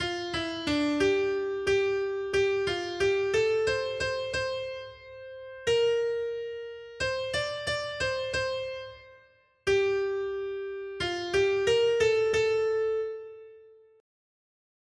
Noty Štítky, zpěvníky ol525.pdf responsoriální žalm Žaltář (Olejník) 525 Skrýt akordy R: Všechno, co dýchá, ať chválí Hospodina! 1.